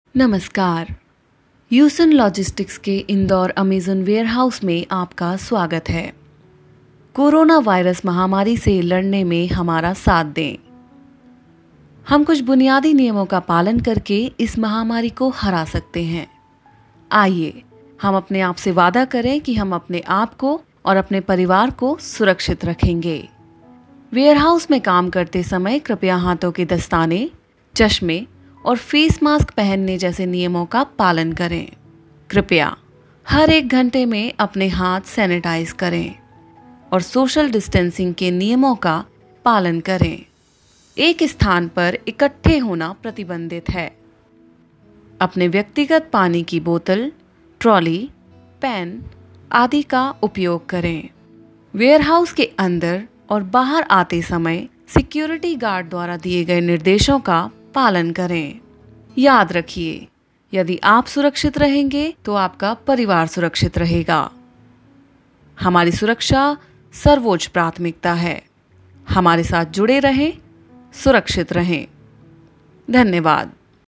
甜美女声